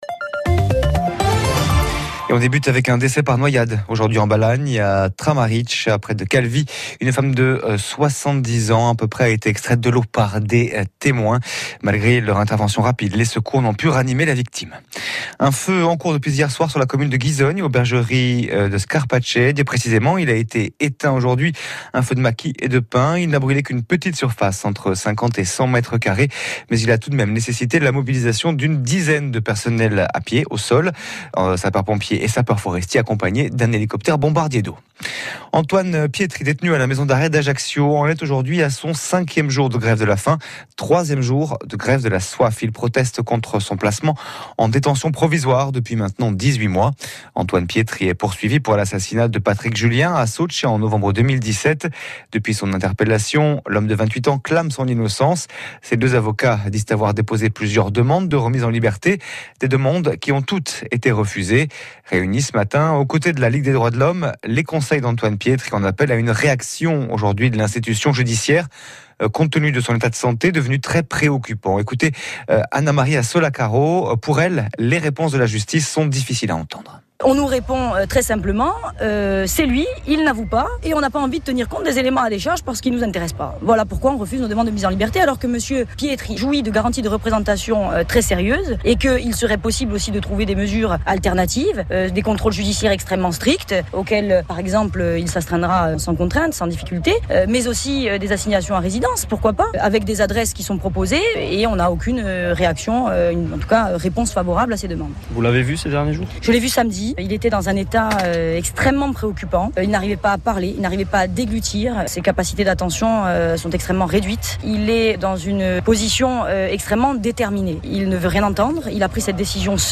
Rcfm Journal de 8h - 12/08 interview